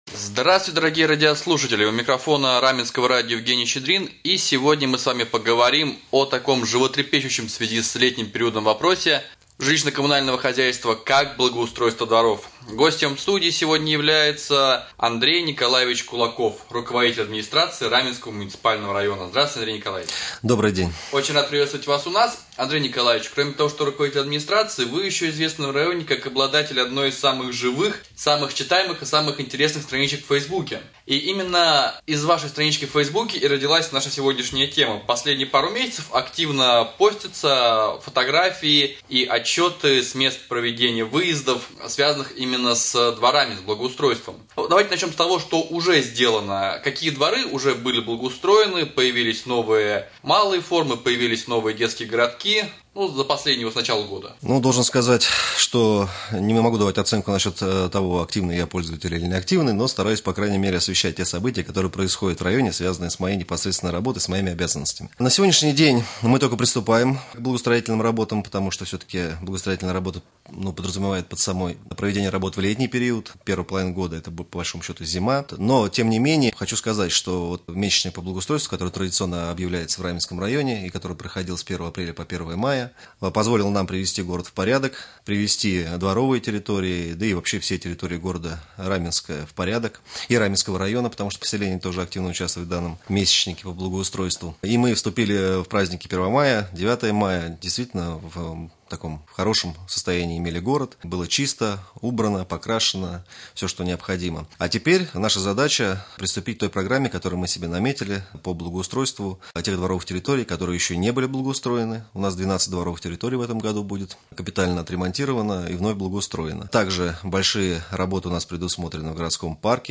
Гость студии Андрей Николаевич Кулаков руководитель администрации Раменского района.
Гость студии руководитель администрации района Андрей Кулаков